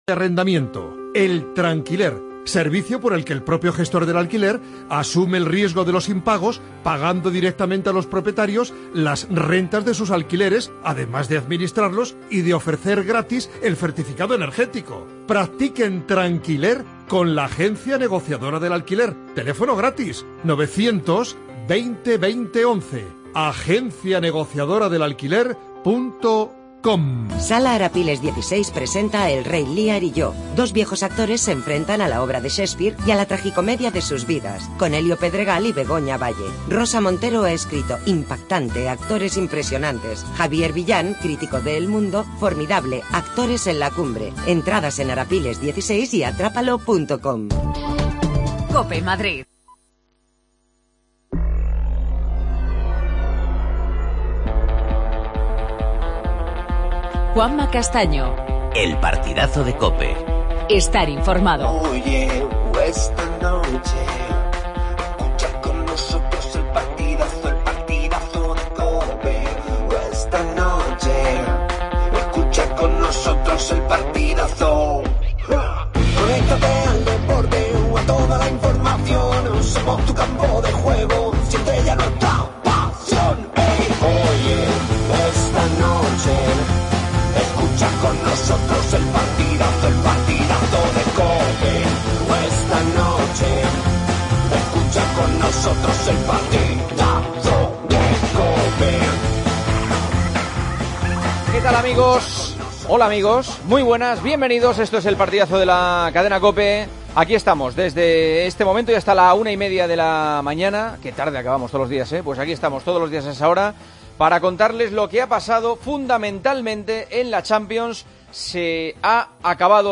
El Atleti se mete en cuartos de final de la Champions por cuarto año consecutivo tras eliminar al Bayer Leverkusen. Entrevista a Lucas Hernández.